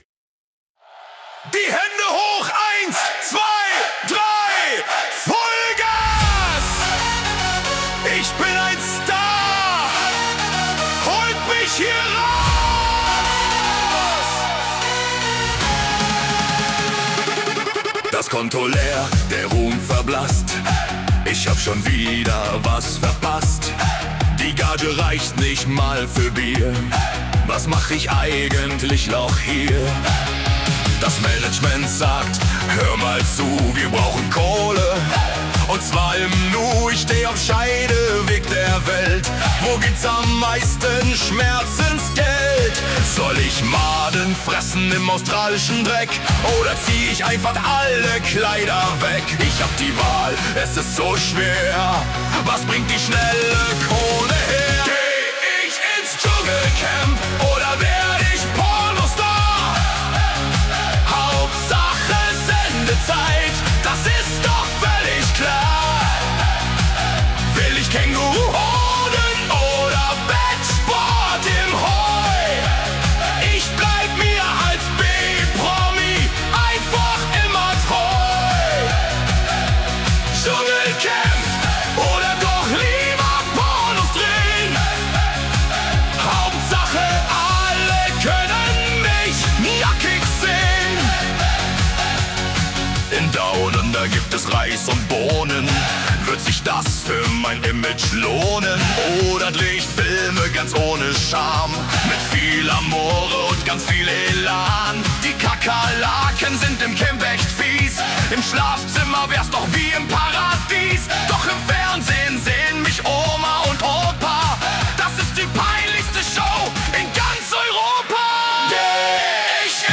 Schlager Version